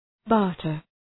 Shkrimi fonetik {‘bɑ:rtər}